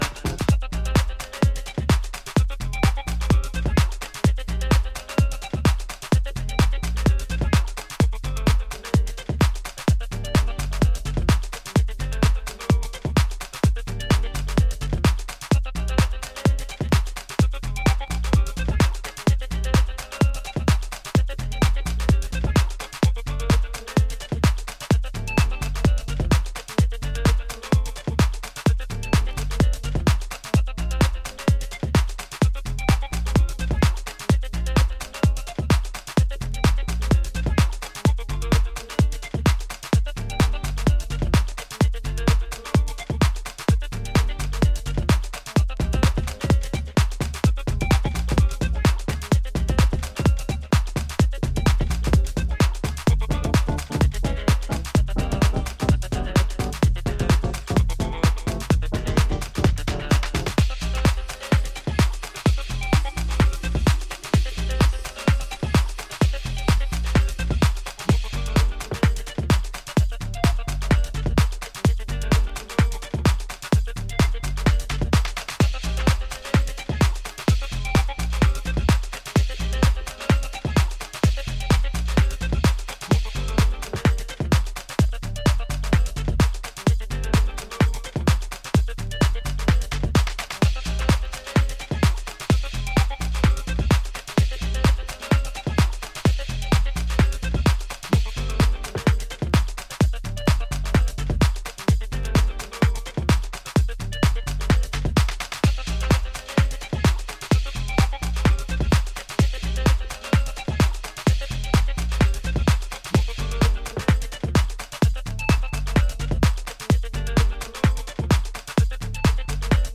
さりげなく技有りのリズムワークとジャズキーでミニマルに魅せる